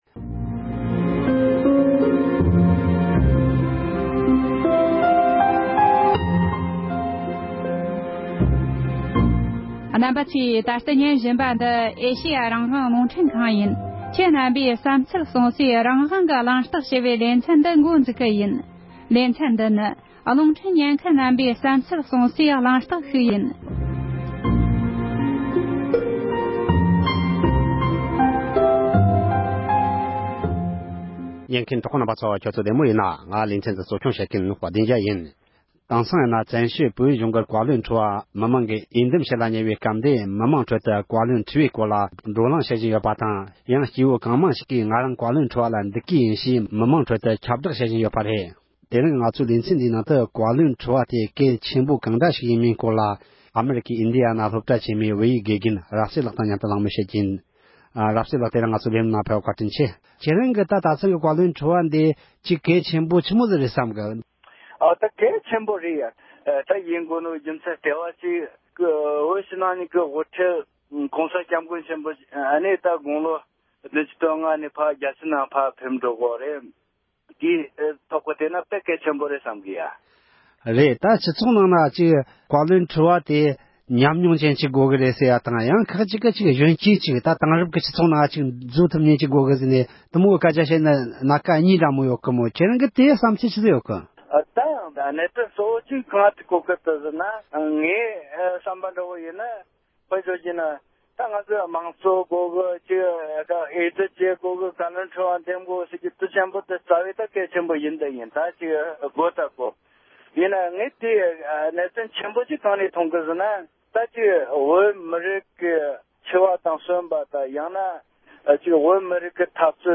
བཀའ་ཁྲི’འི་ཐུཊ་འགན་དང་སྐུ་དབང་སྐོར་བགྲོ་གླེང༌།